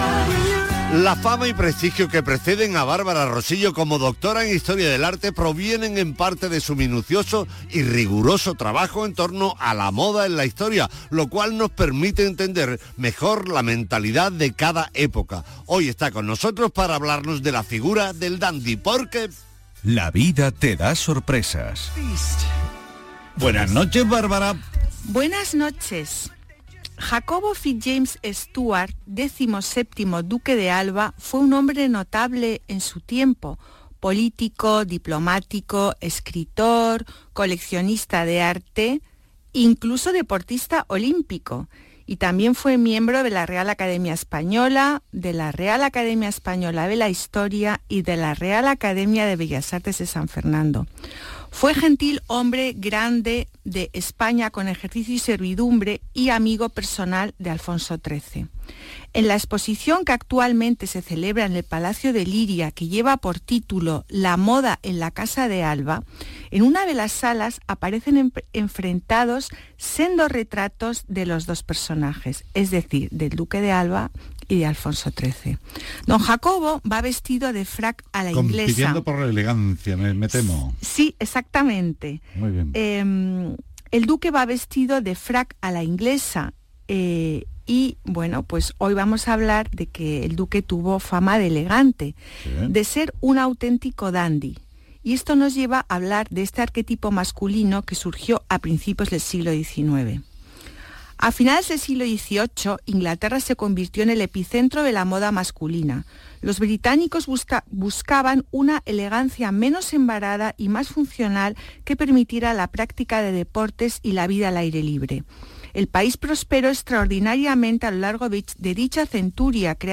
Aquí os dejo mi intervención en el programa de Radio Andalucía Información, «Patrimonio andaluz» del día 17/12/2023